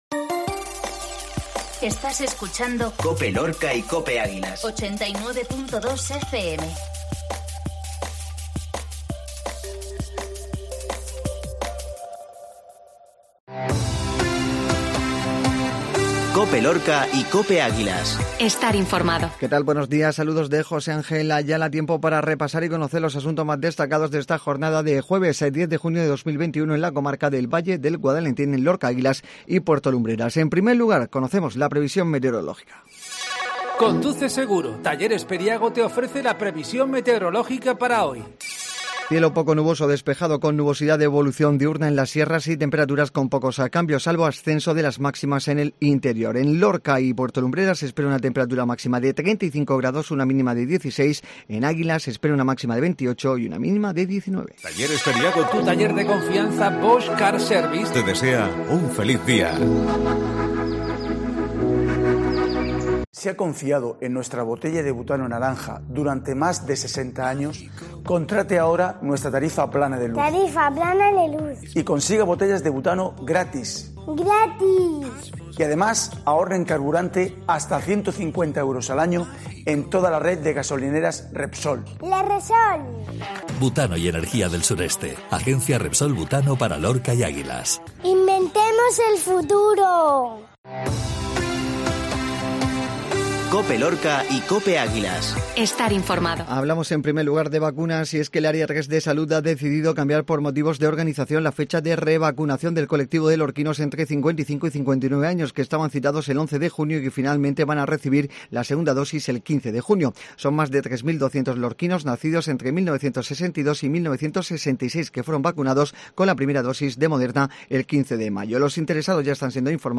INFORMATIVO MATINAL JUEVS